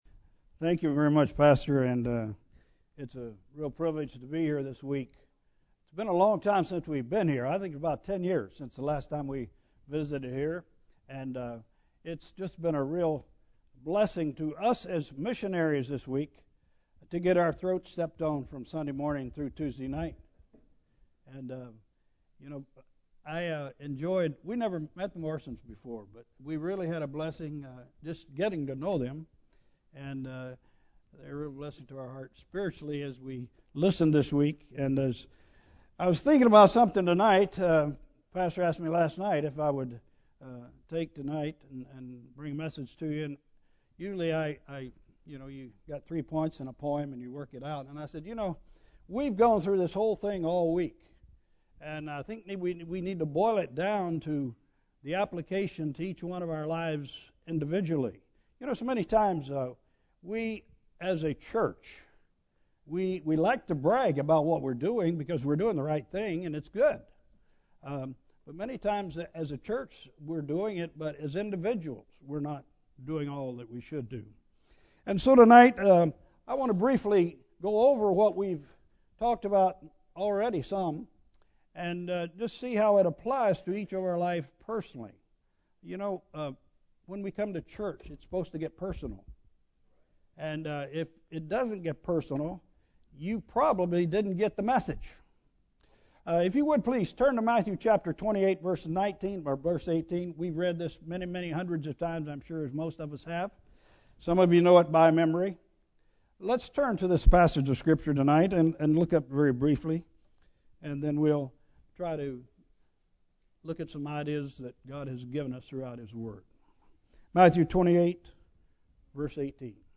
Bible Text: Matthew 18 | Preacher